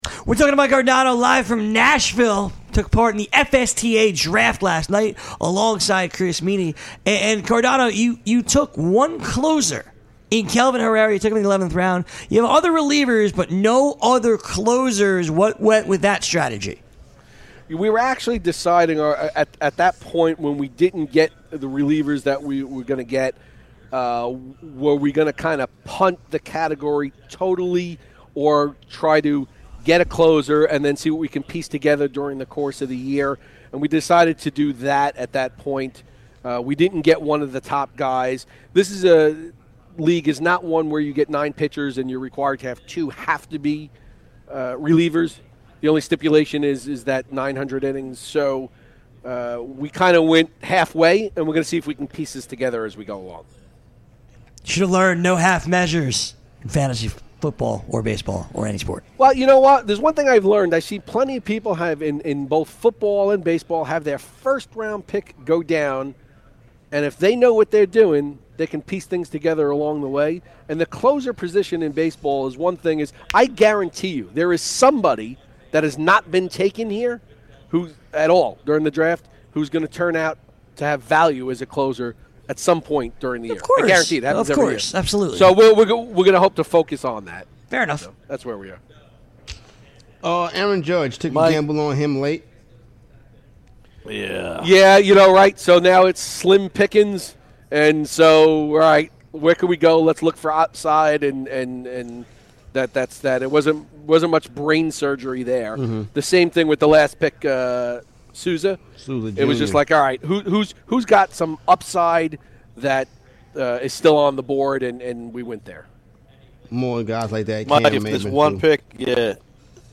Live from Nashville